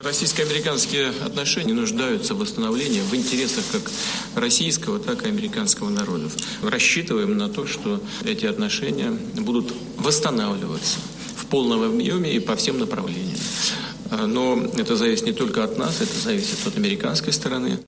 Российский президент Владимир Путин заявил, что Москва рассчитывает на восстановление отношений с США и приветствует высказывания американского президента Дональда Трампа "на эту тему". Об этом Путин сказал, выступая перед журналистами после переговоров в Москве с президентом Словении Борутом Пахором.
Говорит президент России Владимир Путин